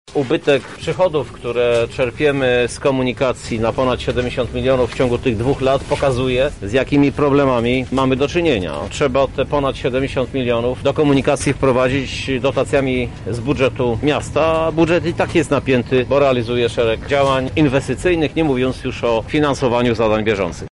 Kolejny rok pandemii to czas, kiedy ubyło nam pasażerów – mówi prezydent miasta Krzysztof Żuk: